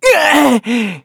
Kibera-Vox_Damage_kr_02.wav